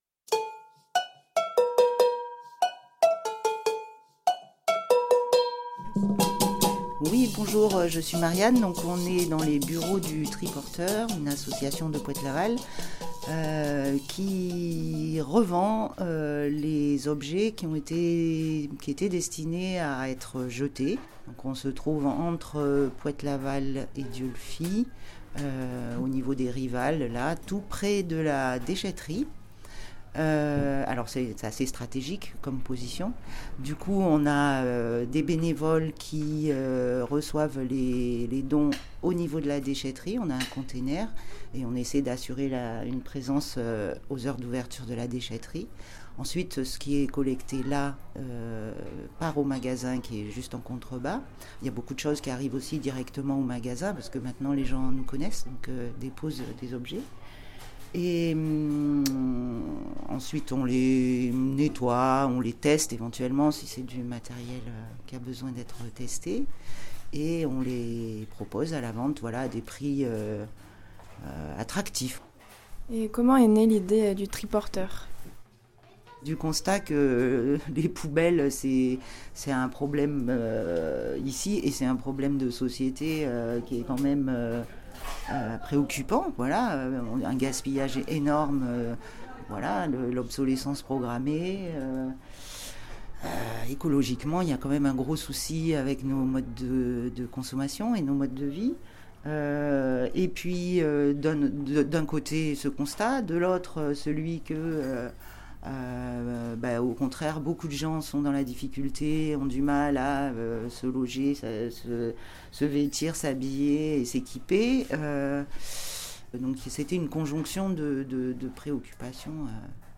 Le tri, c’est porteur : reportage à la recyclerie de Dieulefit
2 octobre 2017 16:15 | Interview, reportage